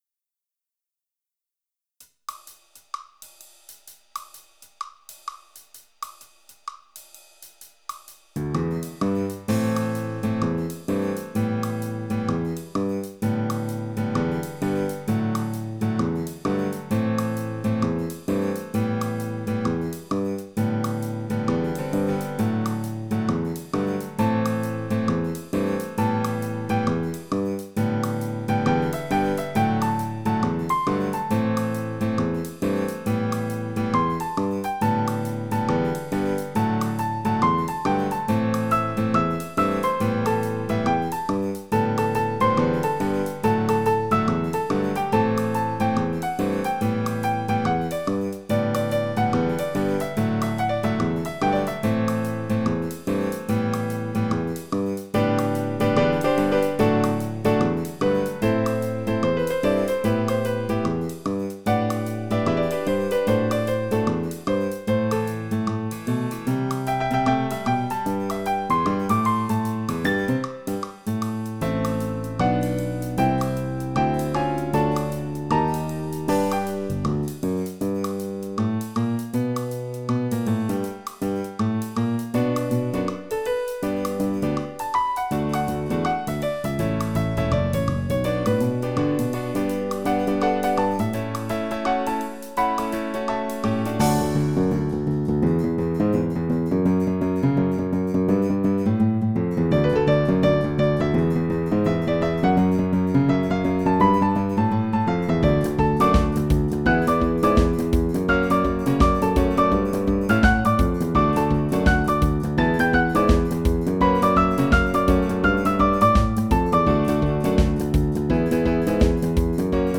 Tags: Trio, Piano, Guitar, Percussion
Title In the Catbird’s Seat Opus # 281 Year 2006 Duration 00:02:33 Self-Rating 3 Description A fun little ditty. mp3 download wav download Files: wav mp3 Tags: Trio, Piano, Guitar, Percussion Plays: 1684 Likes: 0